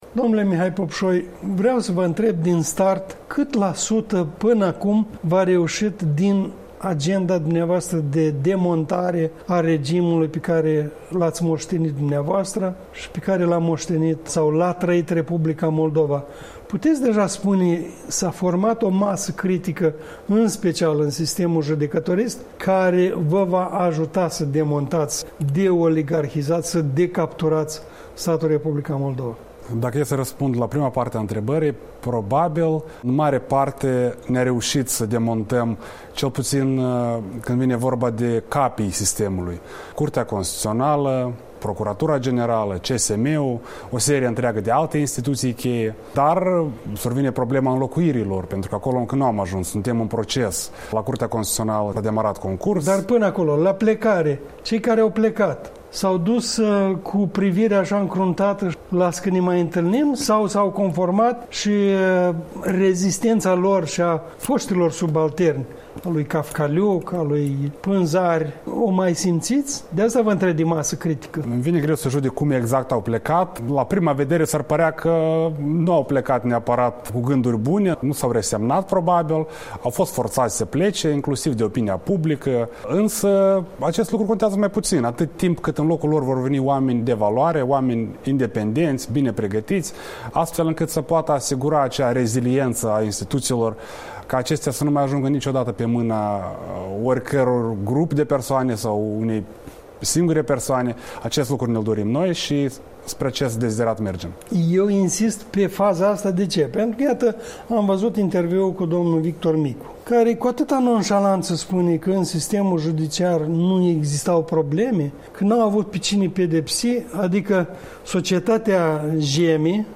Un interviu cu vicepreședintele Parlamentului, membru al fracțiunii Blocului ACUM.